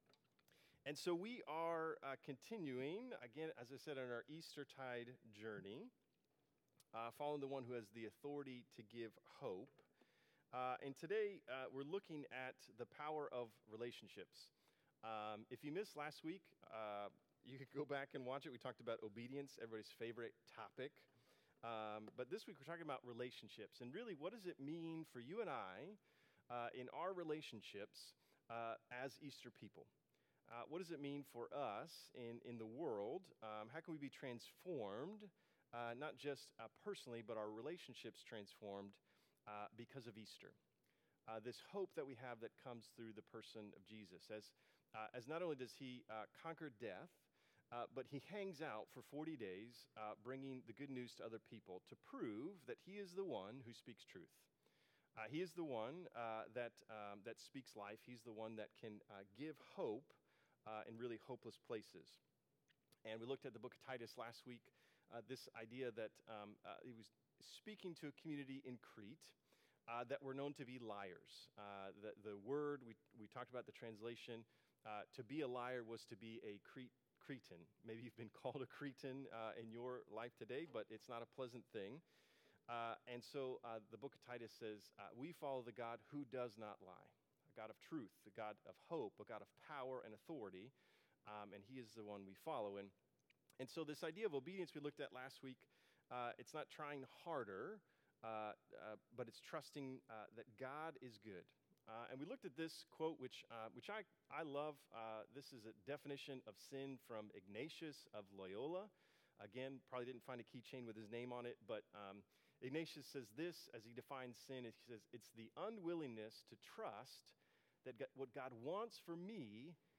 Sermons | Bridge City Church